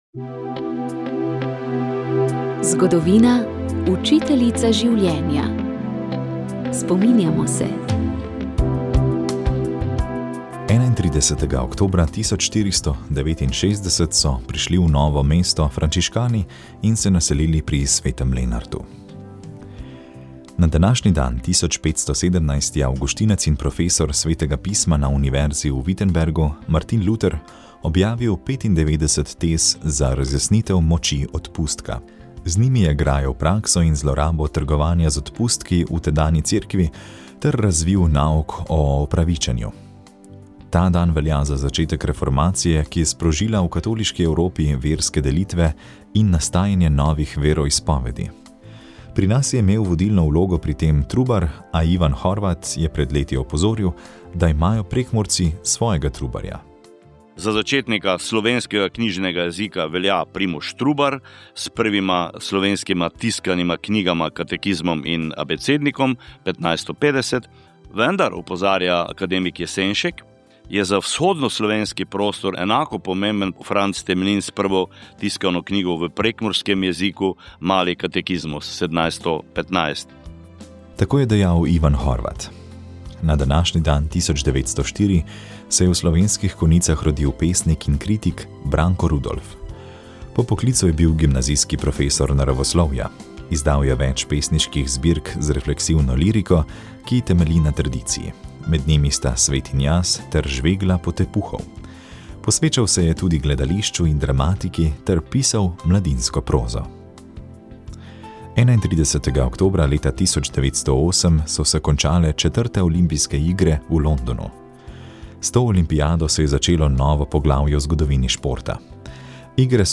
Gostili smo psihiatrinjo